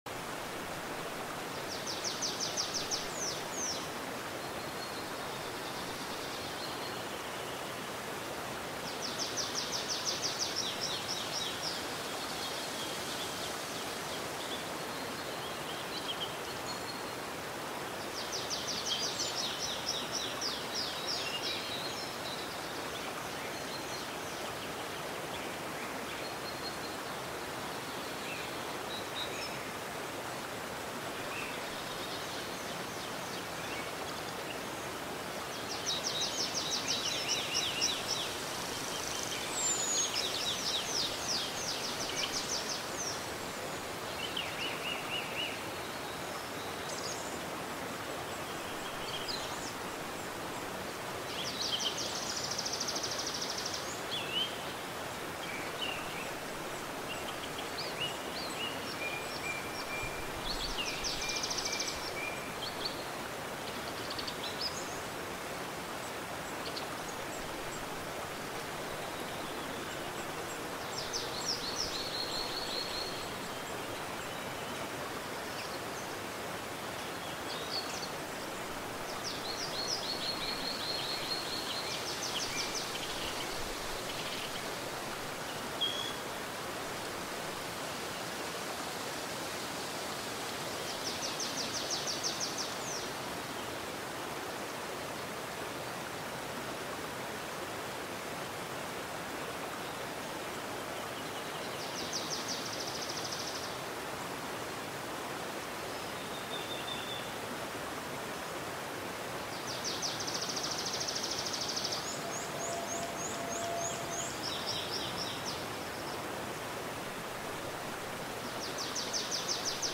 دانلود آهنگ رودخانه و پرندگان 30 دقیقه از افکت صوتی طبیعت و محیط
دانلود صدای رودخانه و پرندگان 30 دقیقه از ساعد نیوز با لینک مستقیم و کیفیت بالا